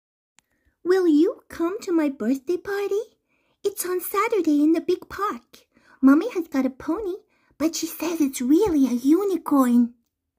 Cartoon little girl US